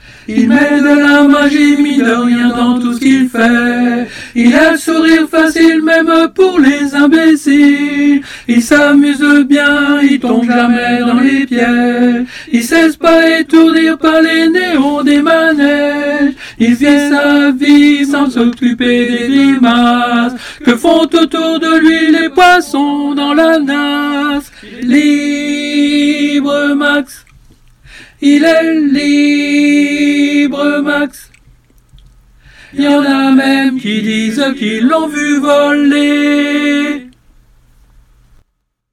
Tenor